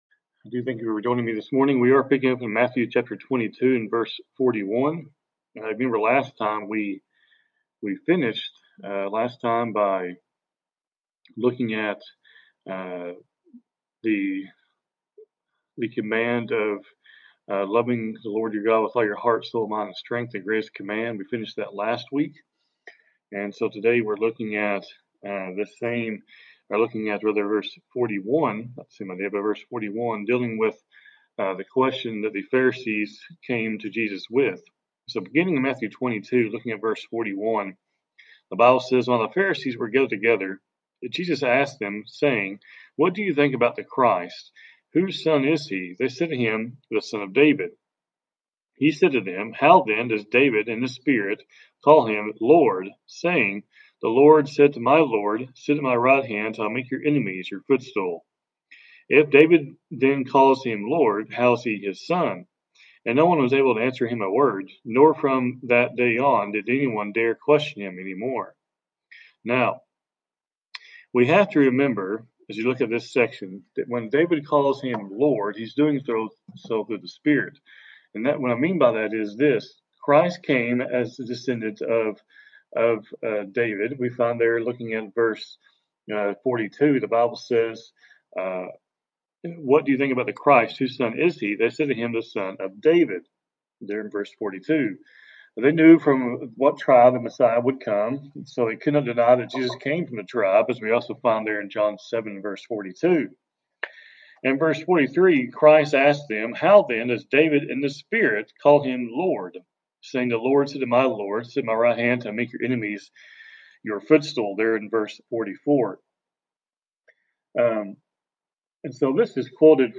Program Info: Live program from the TGRN studio in Mount Vernon, TX